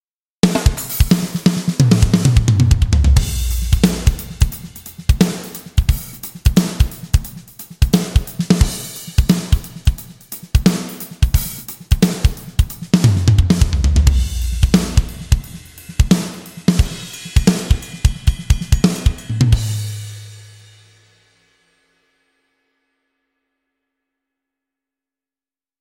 Качество: Моно 48 кГц 24 бита
Описание: Ударная установка
Gigantic low-tuned drums cut through with massive impact, while shimmering cymbals bring energy and brilliance, adding waves of polish and sheen.
Только ударные #3